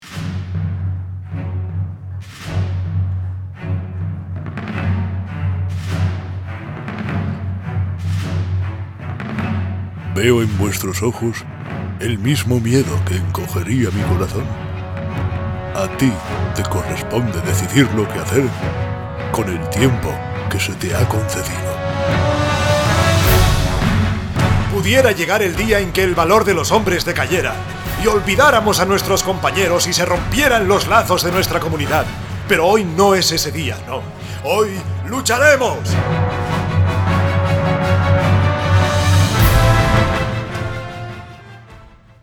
Tengo mi propio estudio de grabación, con microfonía Rode y ordenadores Apple.
kastilisch
Sprechprobe: eLearning (Muttersprache):